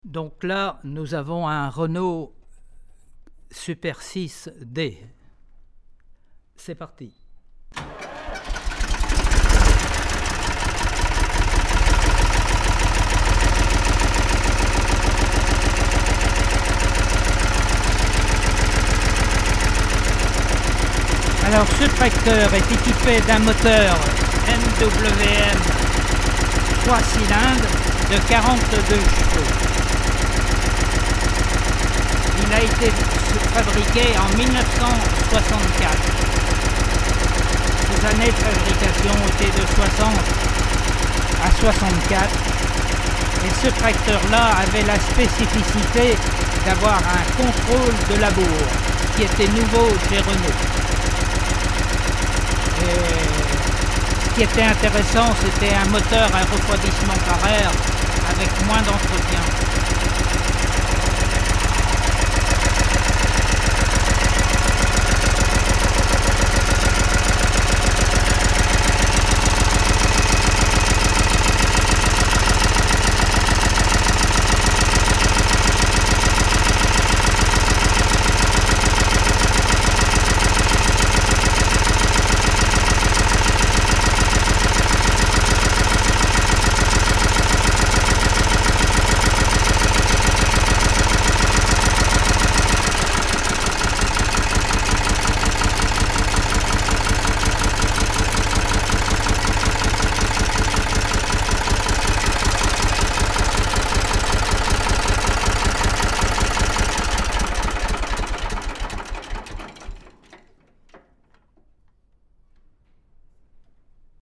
Collection-tracteurs
2_Renault-Super-6-D.mp3